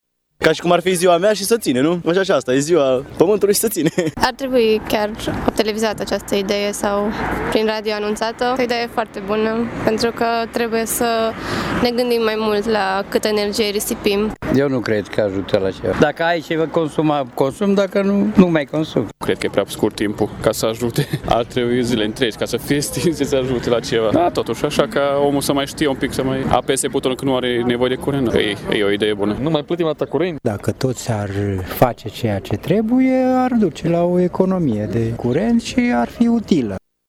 Iată ce cred târgumureșenii despre „Ora pământului”:
vox-ora-pamantului.mp3